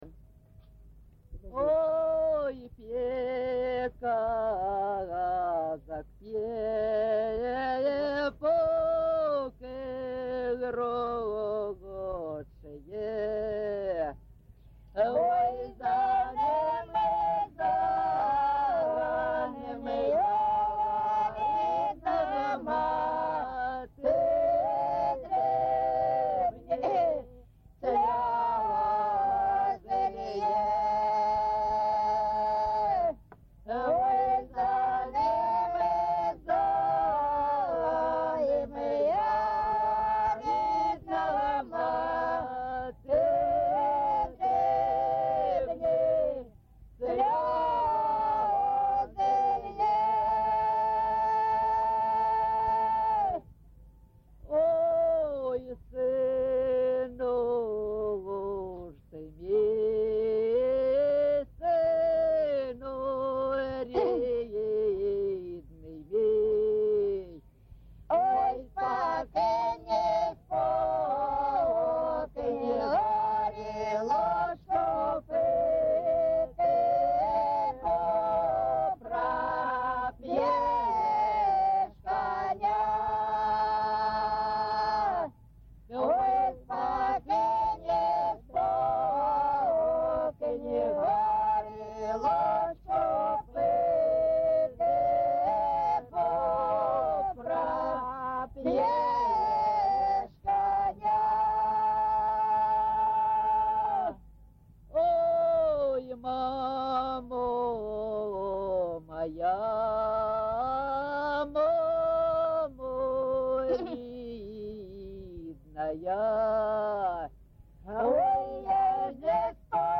ЖанрПісні з особистого та родинного життя, Козацькі, Пʼяницькі
Місце записус. Торське, Краснолиманський район, Донецька обл., Україна, Слобожанщина